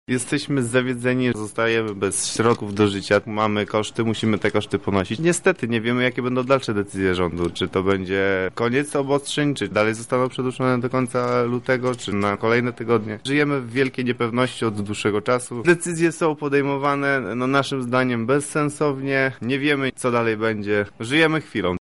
Zapytaliśmy właściciela sklepu odzieżowego w lubelskiej galerii, jak wygląda sytuacja jego przedsiębiorstwa: